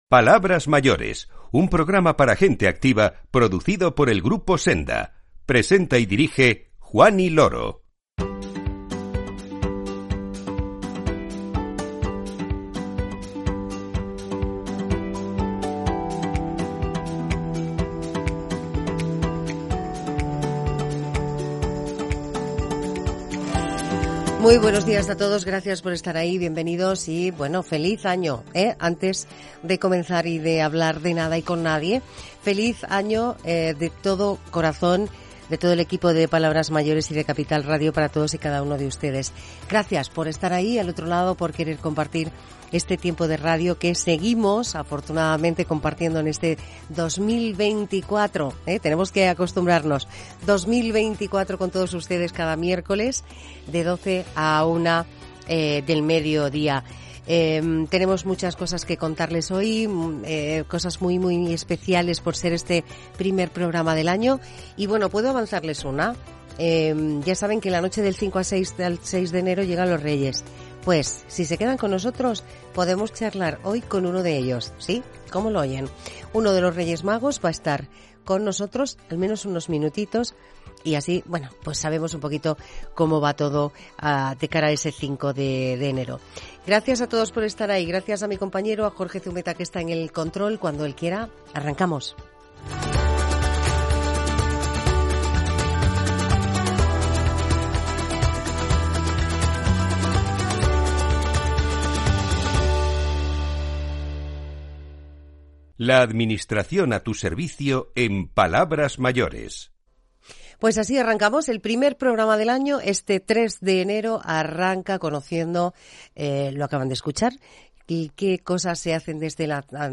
Charlamos en el programa con la Directora General de Personas Mayores, Participación Activa y Soledad No Deseada de la Junta de Andalucía, Rocío Barragán, que nos explica cuáles son las principales políticas en materia de envejecimiento activo que llevan a cabo. La Directora General nos cuenta, además, cuáles son las principales demandas de los mayores andaluces y cómo se trabaja para darle a todas ellas las respuestas que merecen.